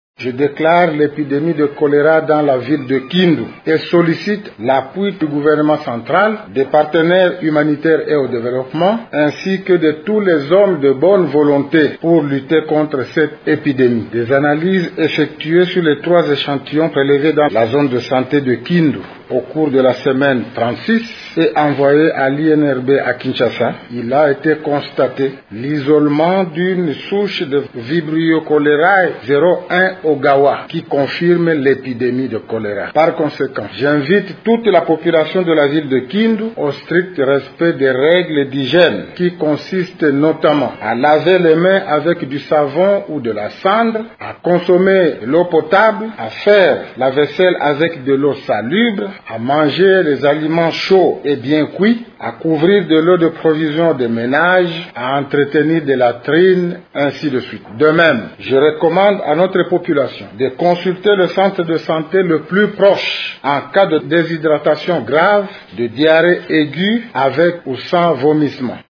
Dans cet extrait sonore, il invite la population locale à observer strictement les règles d’hygiène: